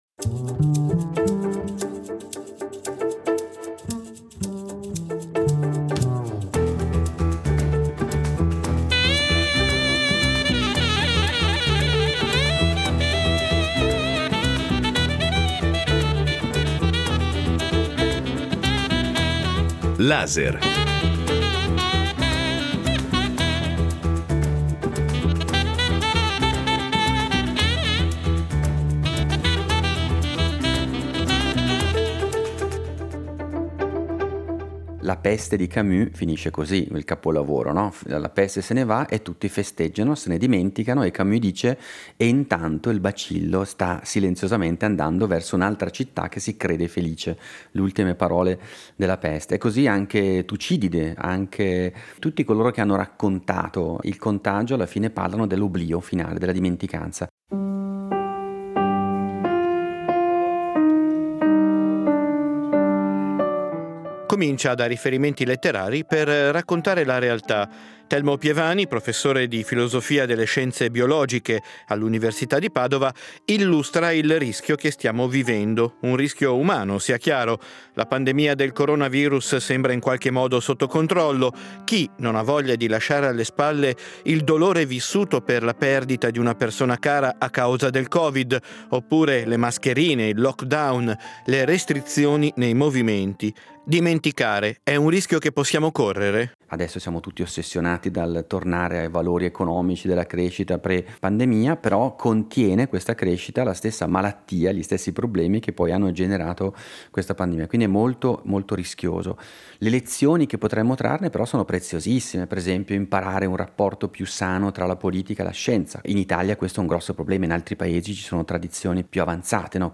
Incontro con Telmo Pievani